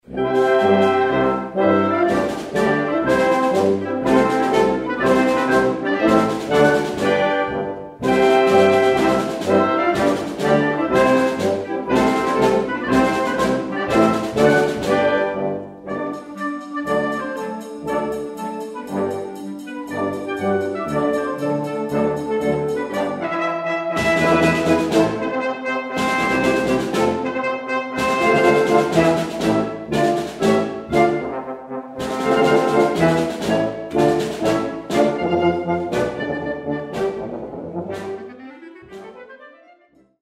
marsze